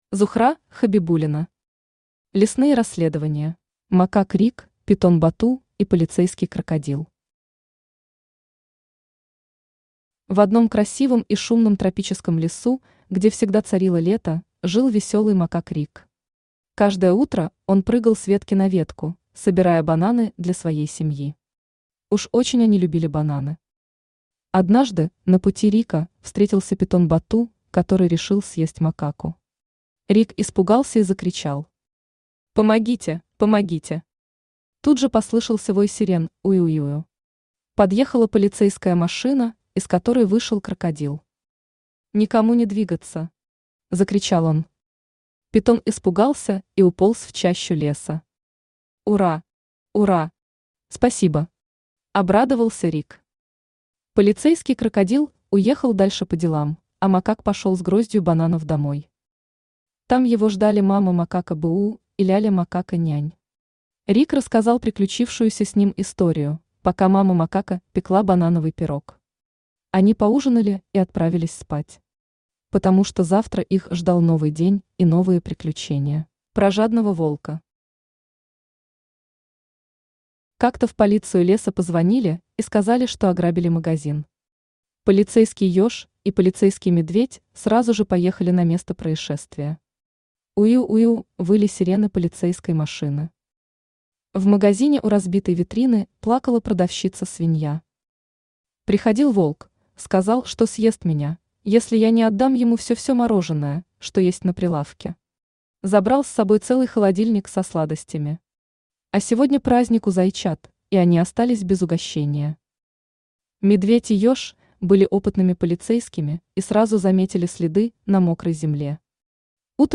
Аудиокнига Лесные расследования | Библиотека аудиокниг
Aудиокнига Лесные расследования Автор Зухра Хабибуллина Читает аудиокнигу Авточтец ЛитРес.